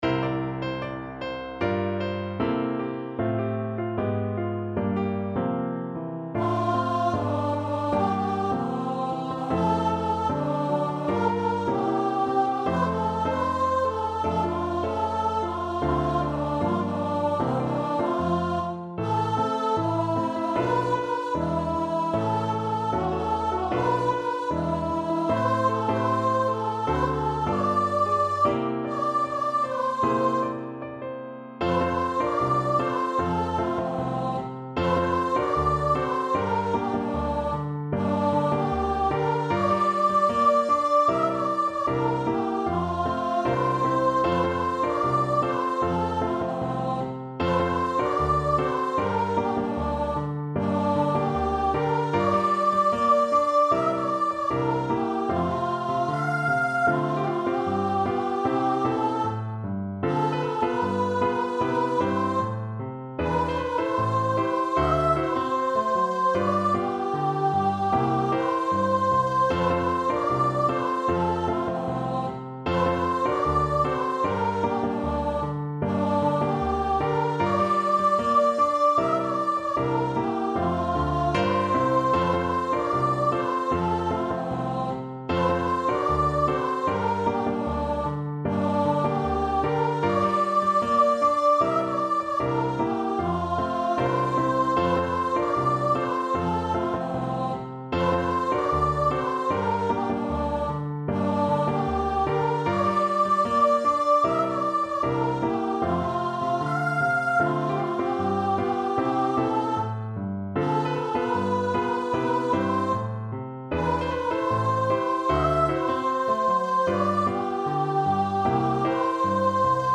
2/2 (View more 2/2 Music)
Pop (View more Pop Voice Music)